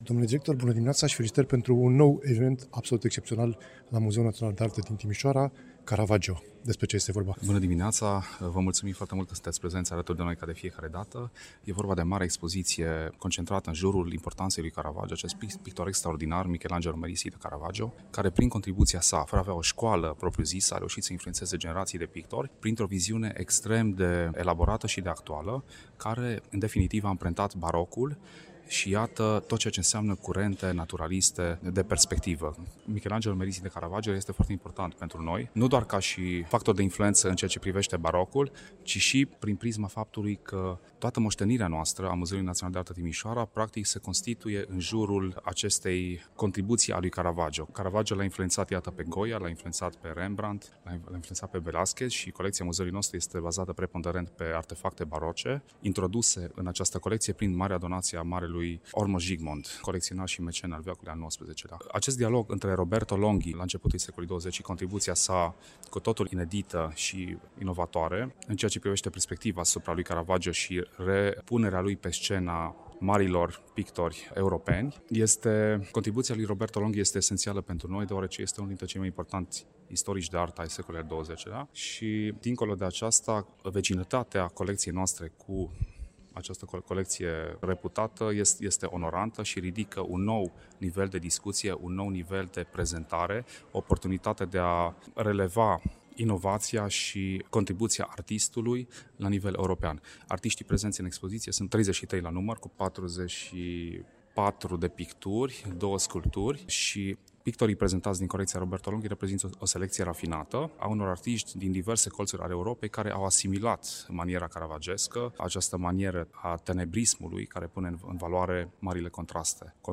Am discutat la vernisaj